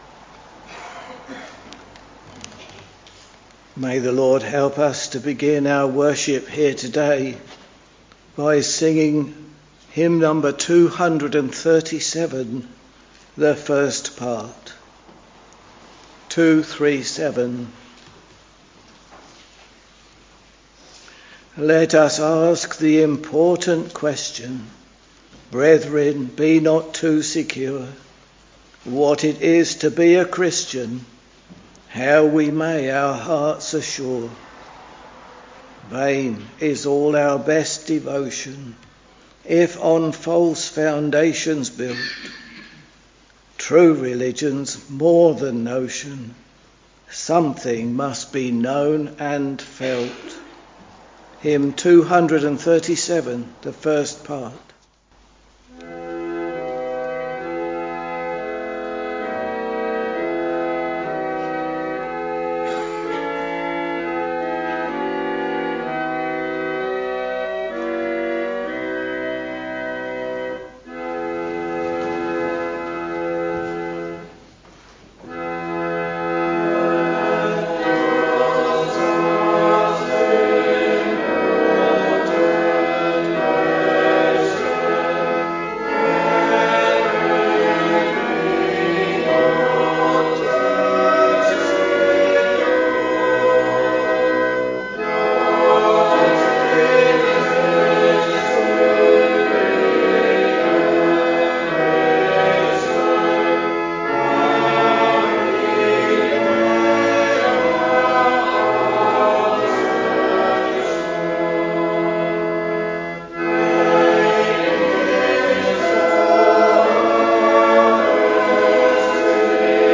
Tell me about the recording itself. Monday, 26th August 2024 — Afternoon Service Preacher